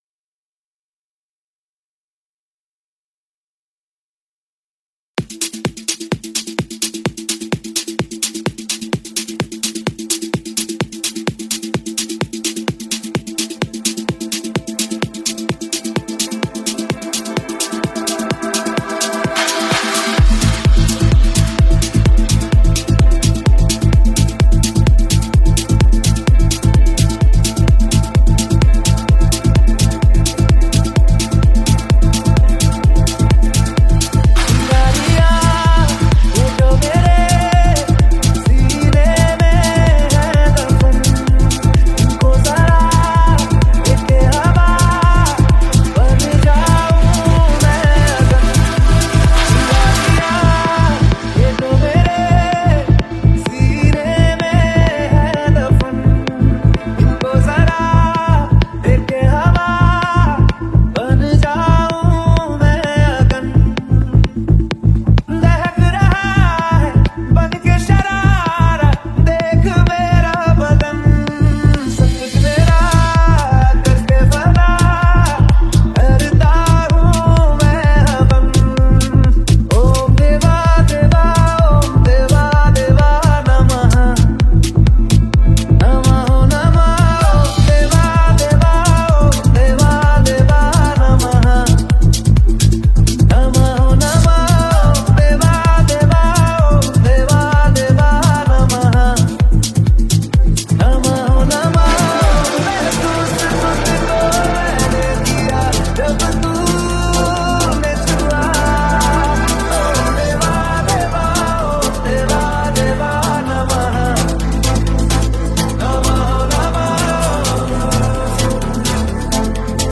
New Dj Song 2023